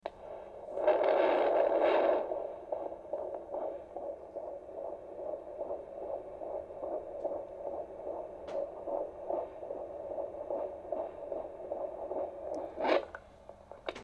Коллекция включает разные варианты записей, от монотонных сигналов до динамичных изменений.
Звук работающего доплера и стук сердца малыша в животе